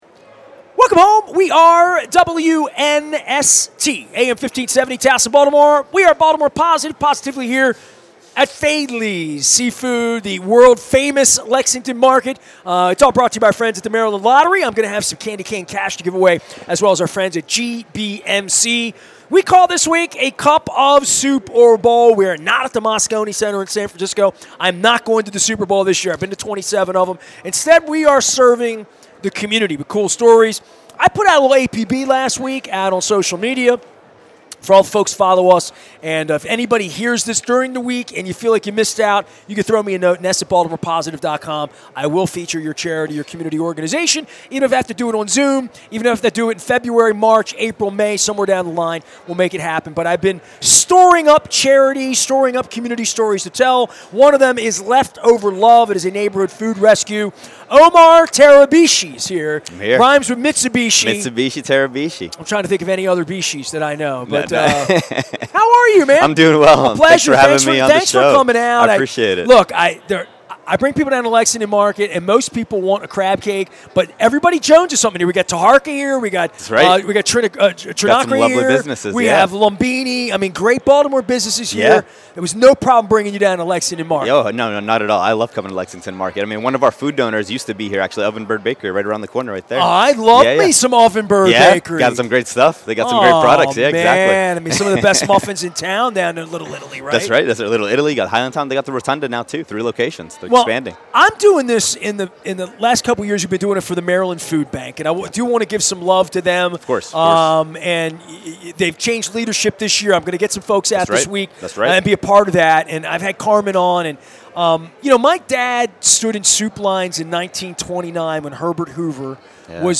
at Faidley's Seafood in Lexington Market